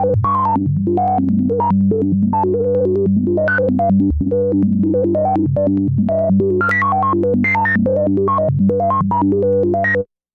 标签： MIDI-速度-96 FSharp4 MIDI音符-67 罗兰-SH-2 合成器 SI ngle音符 多重采样
声道立体声